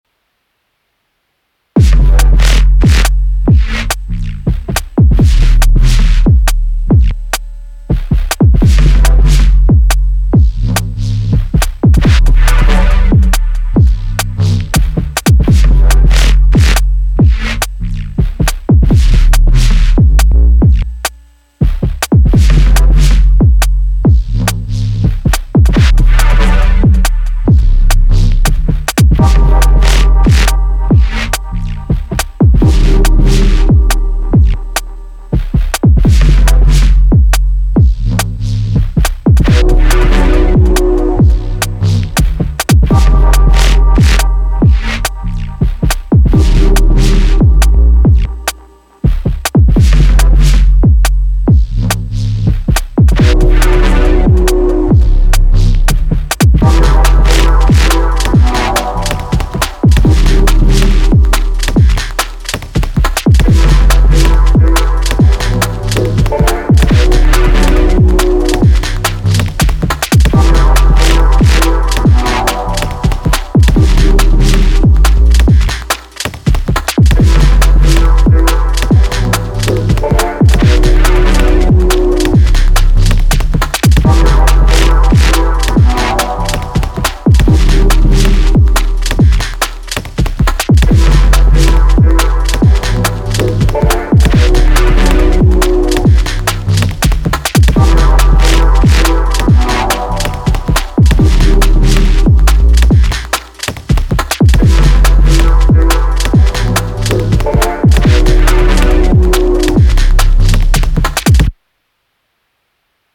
edit: did all channels separatly in Live and then did new master.
It wasn’t the output or cables, it was input and cables from Roland S1(that thing is noisy)